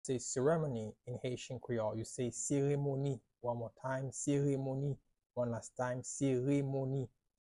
“Ceremony” in Haitian Creole – “Seremoni” pronunciation by a native Haitian tutor
“Seremoni” Pronunciation in Haitian Creole by a native Haitian can be heard in the audio here or in the video below:
How-to-say-Ceremony-in-Haitian-Creole-–-Seremoni-pronunciation-by-a-native-Haitian-tutor.mp3